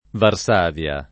[ var S# v L a ]